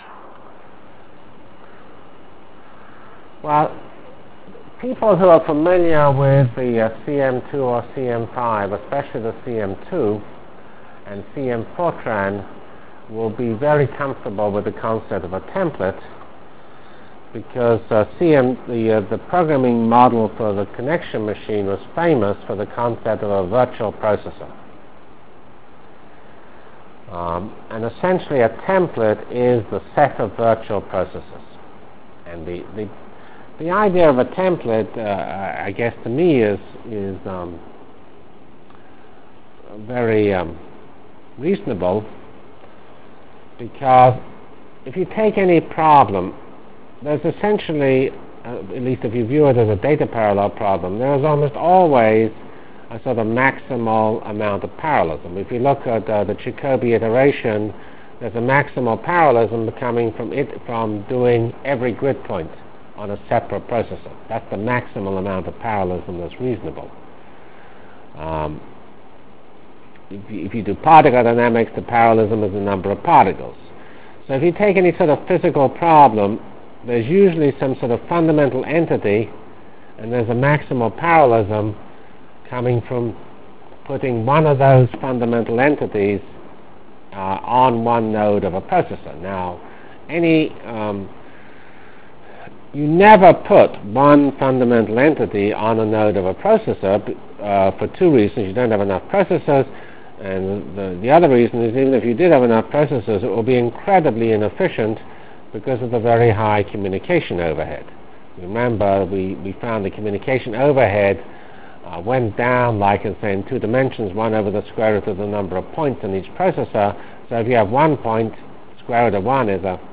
From CPS615-Introduction to Virtual Programming Lab -- Problem Architecture Continued and Start of Real HPF Delivered Lectures of CPS615 Basic Simulation Track for Computational Science -- 26 September 96. *